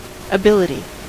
Ääntäminen
US : IPA : /ə.ˈbɪl.ə.ti/